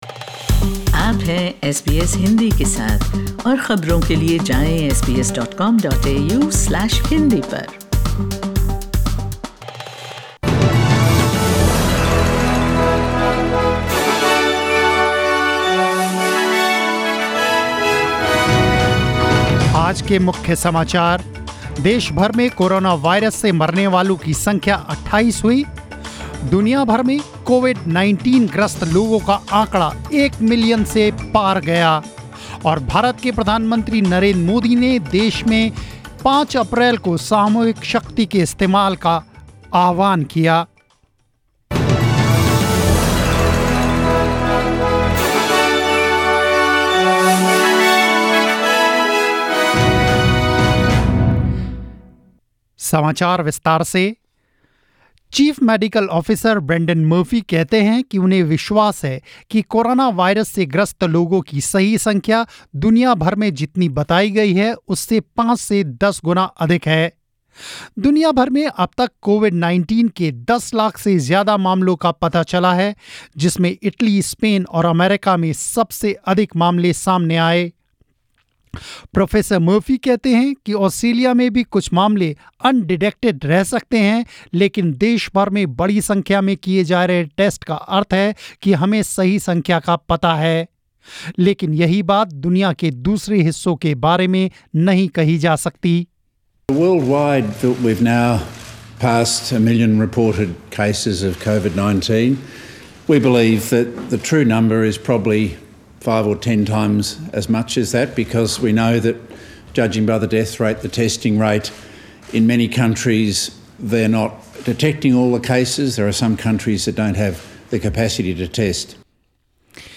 News in Hindi 3rd April 2020